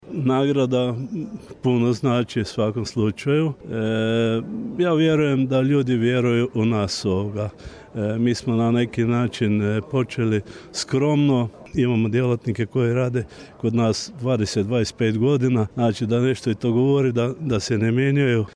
Na svečanosti održanoj u Prelogu Obrtnička komora Međimurske županije obilježila je 27 godina rada i tom prilikom nagrađeni su uspješni obrtnici, o čemu smo izvijestili u našem programu.